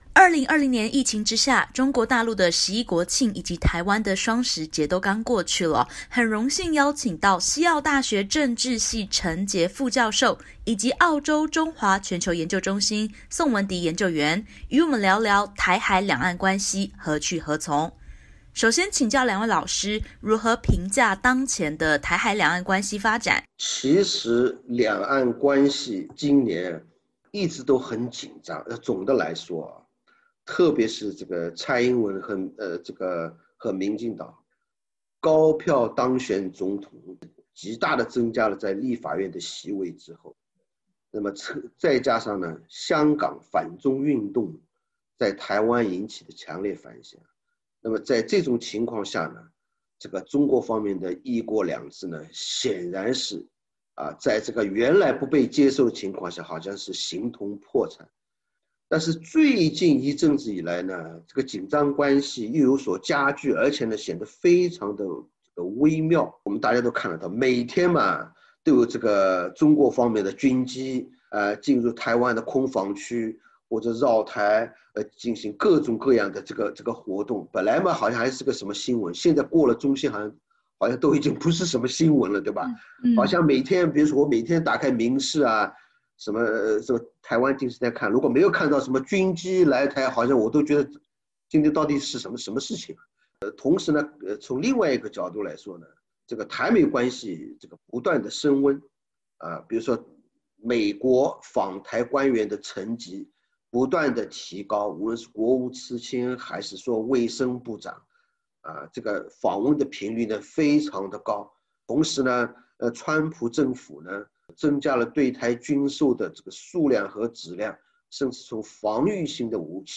澳中关系紧张、美台关系升温、美国总统大选如何影响台海走势？欢迎点击首图，收听完整采访音频。